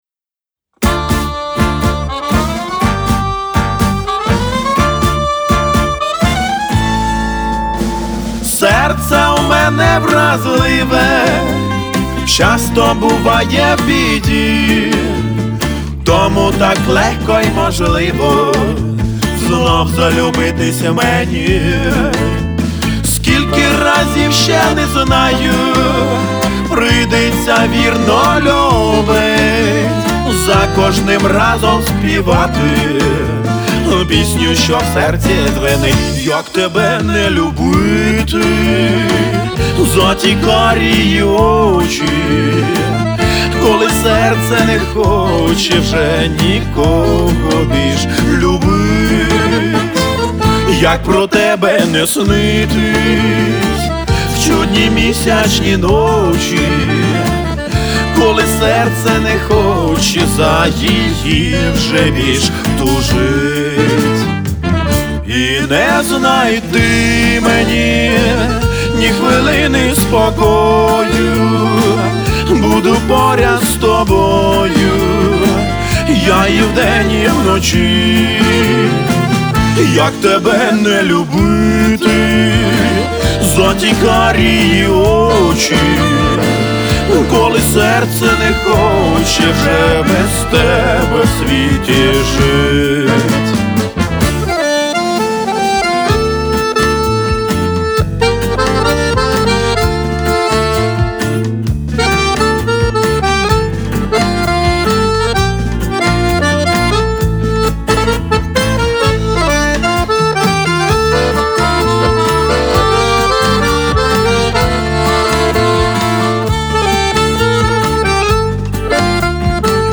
Стиль : retro pop